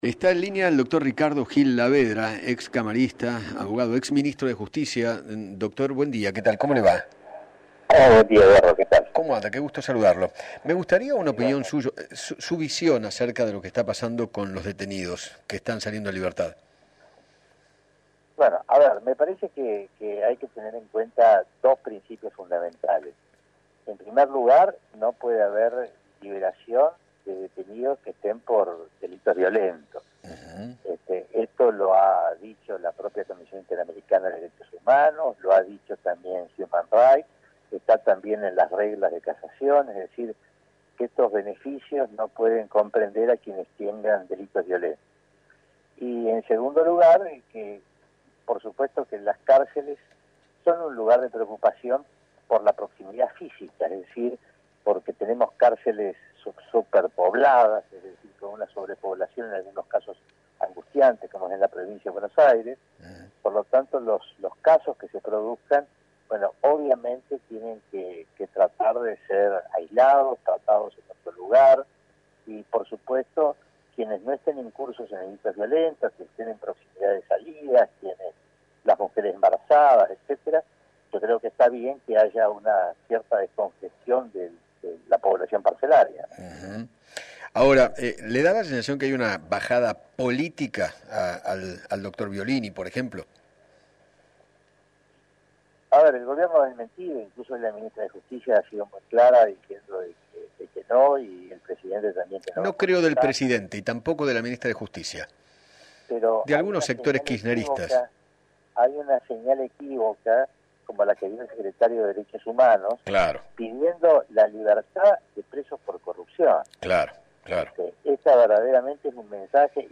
Ricardo Gil Lavedra, abogado y ex Ministro de Justicia, dialogó con Eduardo Feinmann sobre la polémica liberación de presos en medio de la pandemia para evitar masivos contagios.